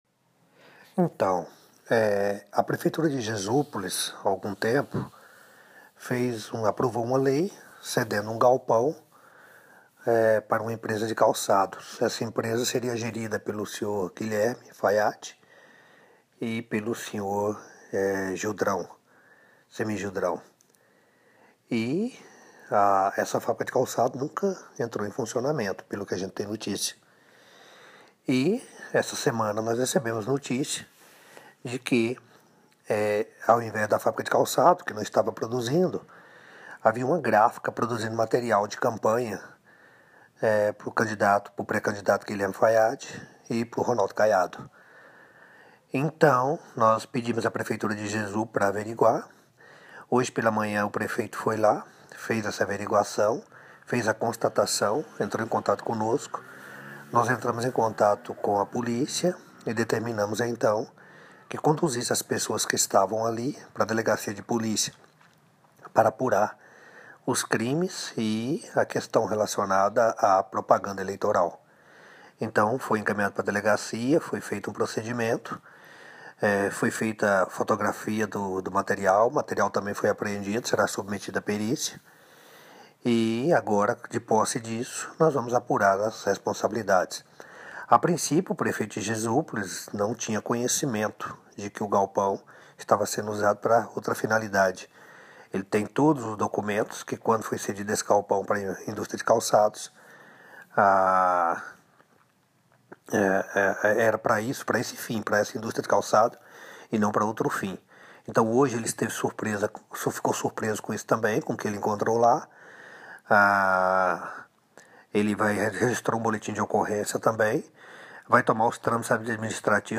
Promotor fala sobre gráfica que produzia material de campanha em Jesúpolis